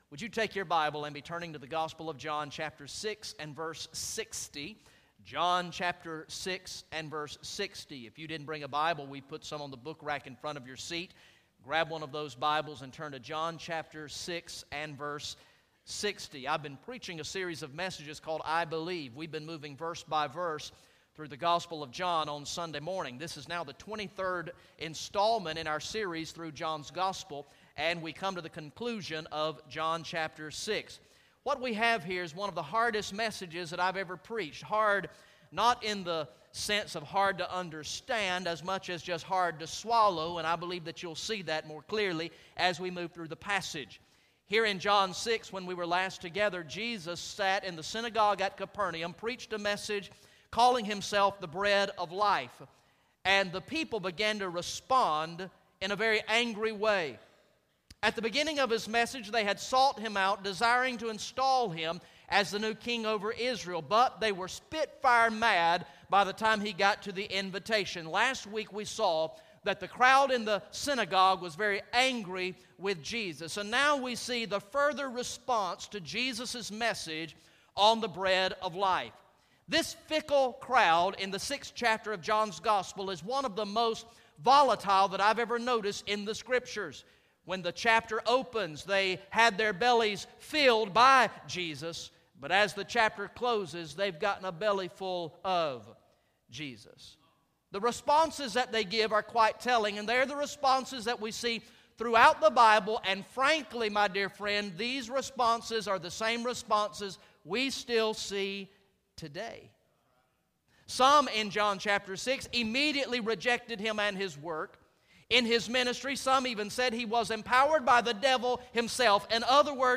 Message #23 from the sermon series through the gospel of John entitled "I Believe" Recorded in the morning worship service on Sunday, October 26, 2014